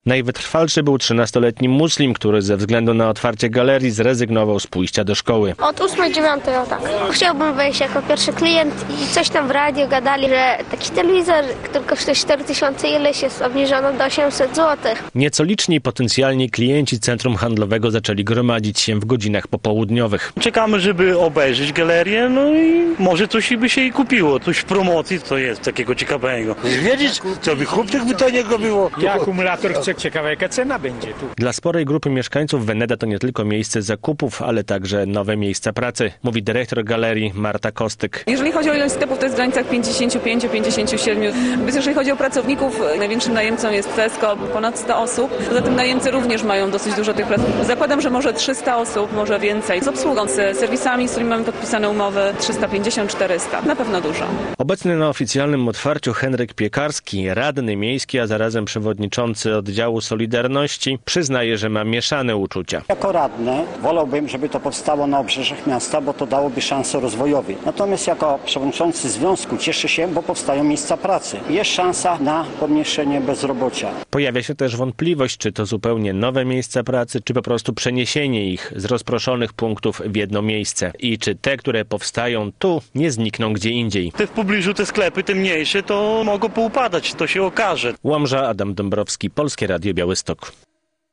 Otwarcie Galerii Veneda - relacja